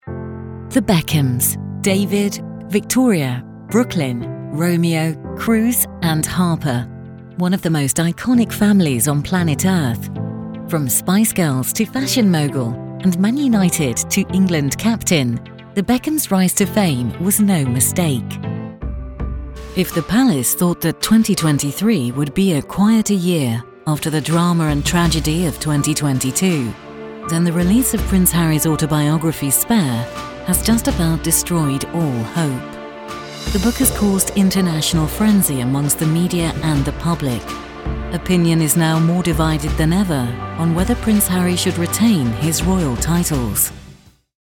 Narration
An experienced and versatile British female voice over, recording for clients around the globe from my professional home studio in the UK.
My voice has been described as warm with gentle gravitas – I love performing deep and modulated narrations – though I can definitely switch things up for more fun and bubbly reads.
Home studio with purpose-built floating isolation booth